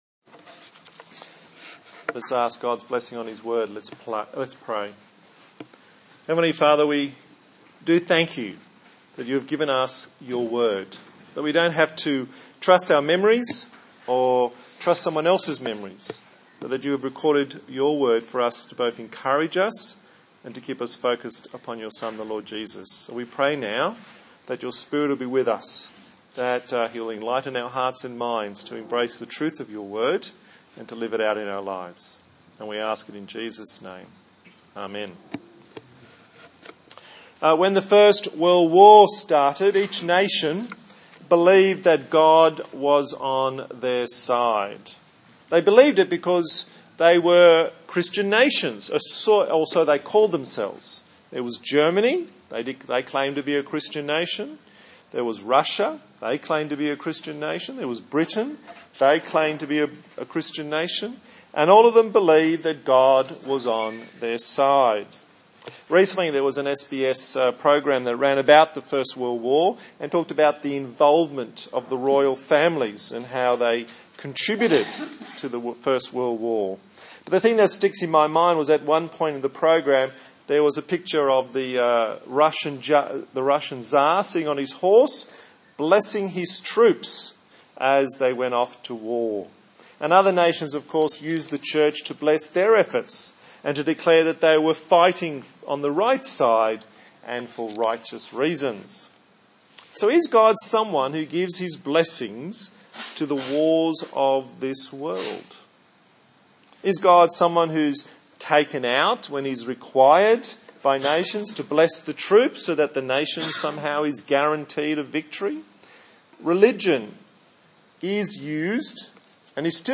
A sermon on Psalm 20